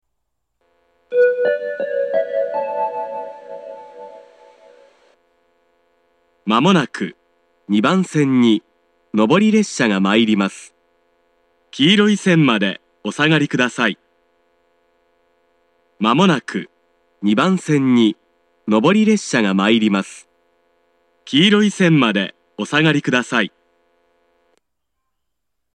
仙石型（男性）
接近放送
1番線と同様、信号開通して1,2分後に流れます。